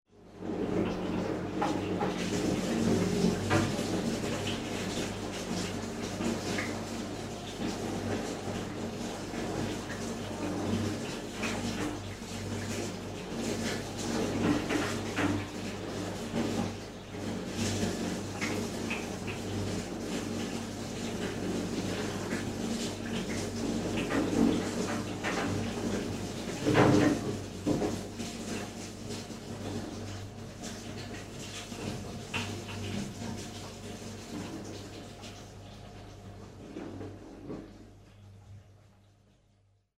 Ruido de ducha de los vecinos
ducha
Sonidos: Agua
Sonidos: Acciones humanas
Sonidos: Hogar